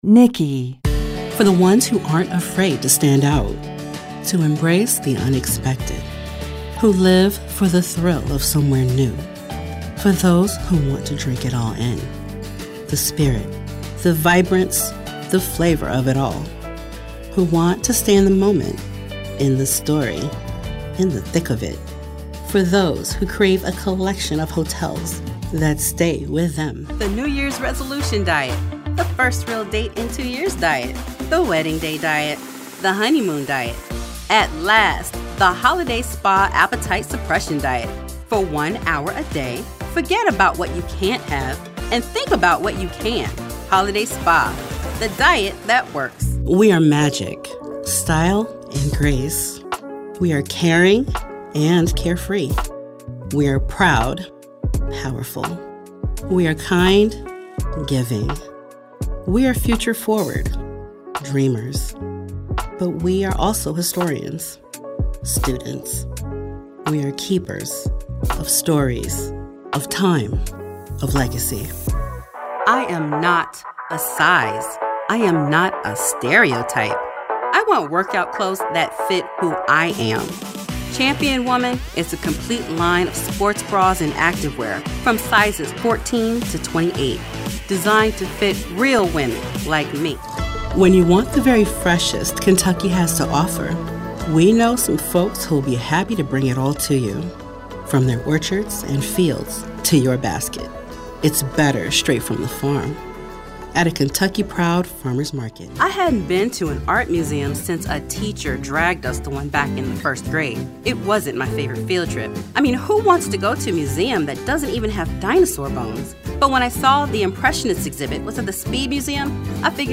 Showcase Demo - 2026